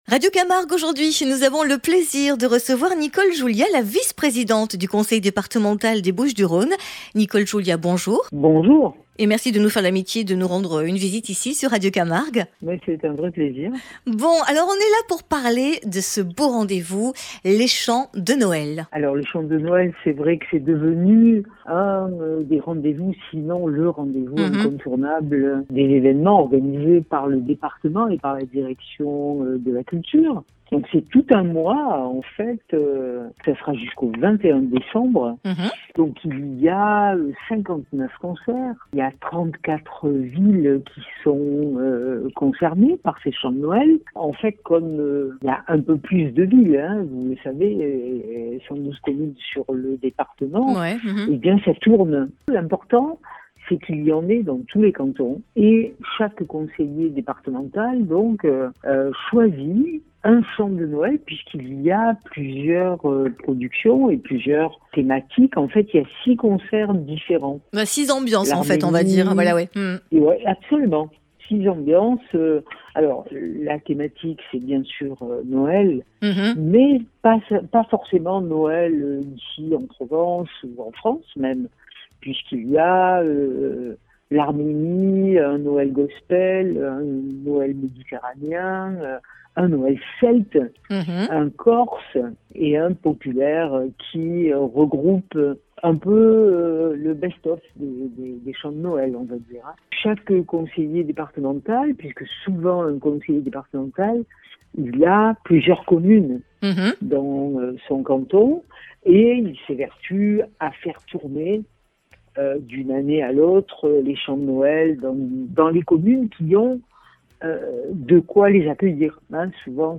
radio camargue podcast hebdo interview radio camargue Du 1er au 21 décembre 0:00 5 min 20 sec 4 décembre 2025 - 5 min 20 sec Présentation de la tournée des chants de Noël En attendant les fêtes, le Département organise la 33e édition des Chants de Noël avec pas moins de 59 concerts gratuits sur tout le territoire.